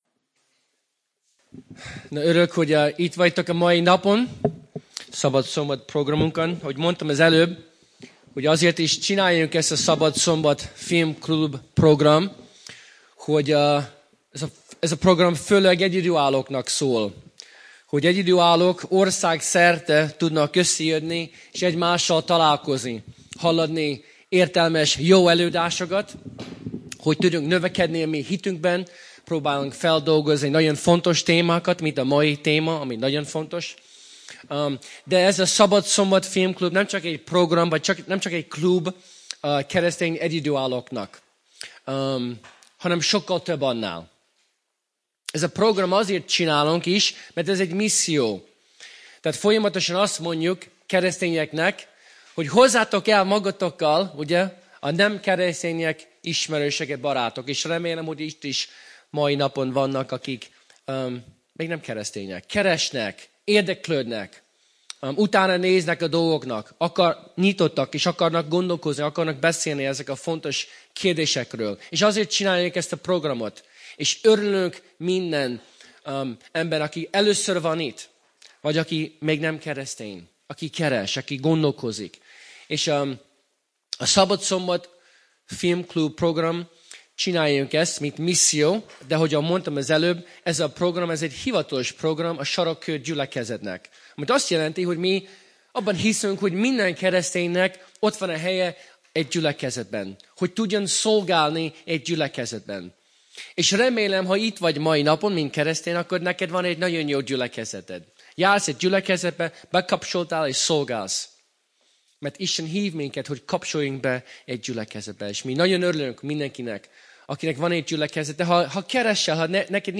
Előadások